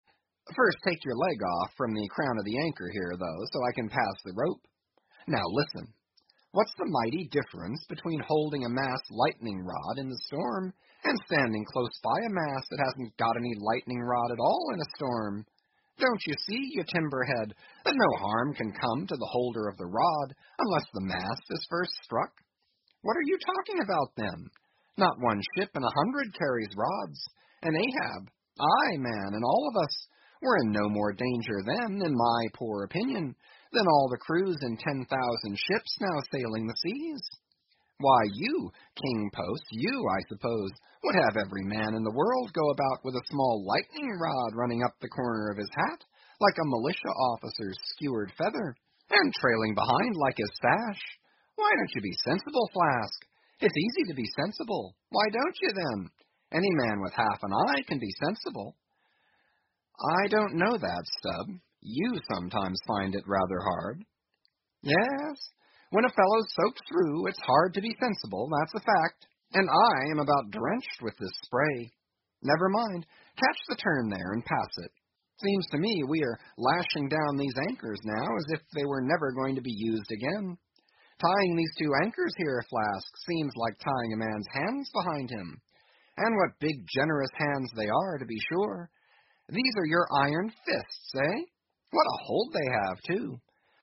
英语听书《白鲸记》第950期 听力文件下载—在线英语听力室